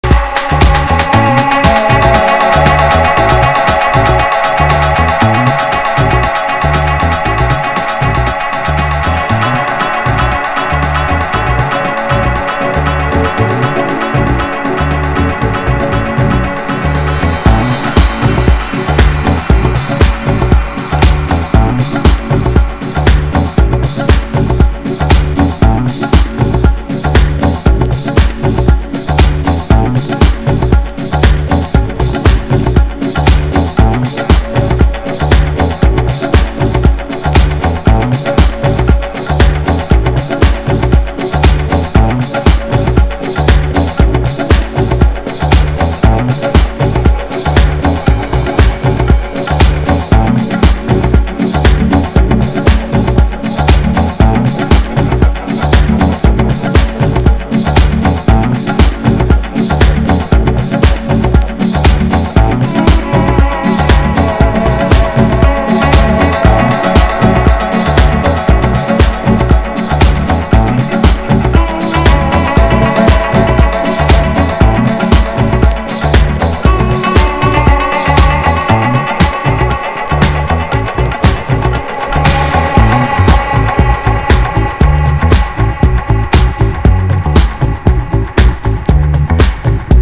INDIE DANCE# NU-DISCO / RE-EDIT